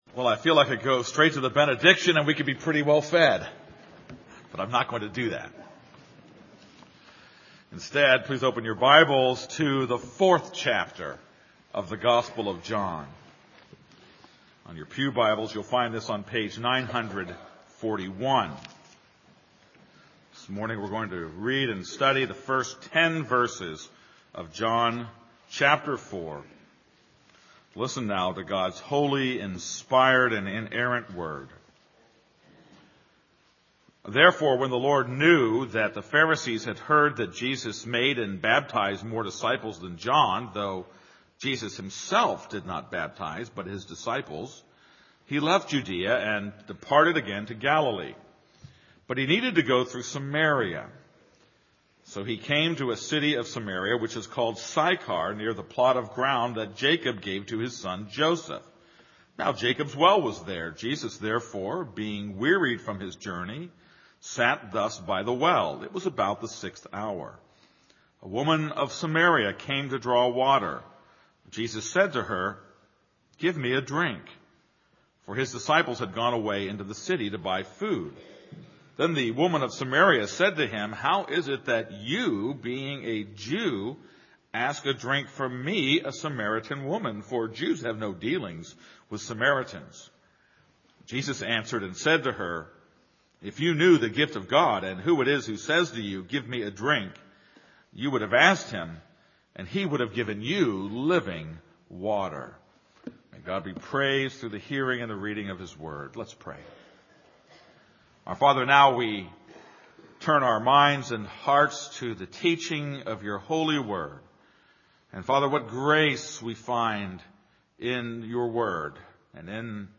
This is a sermon on John 4:1-10.